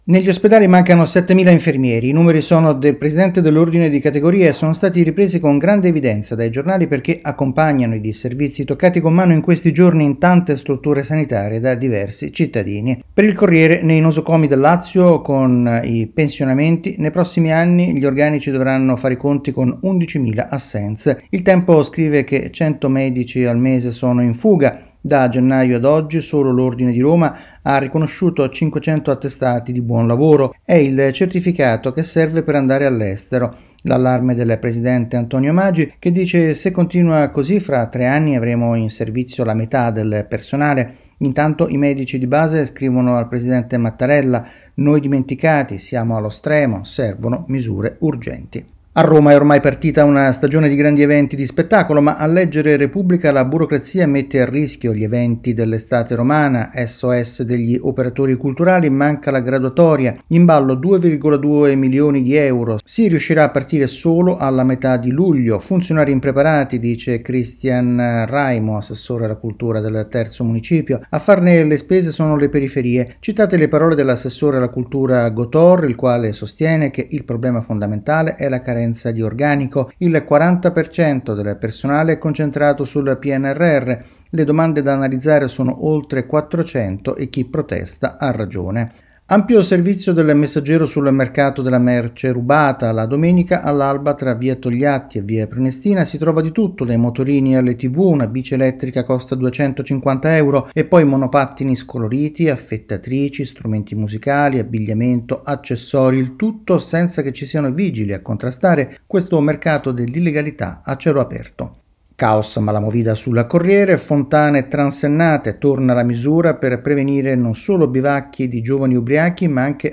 rassegna stampa